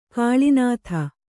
♪ kāḷinātha